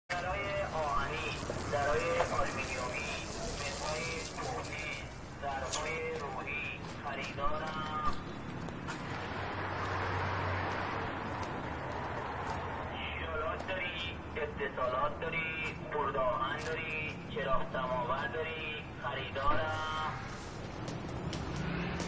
صدای ضبط شده خریدار ضایعات
صدای-ضبط-شده-خریدار-ضایعات-2.mp3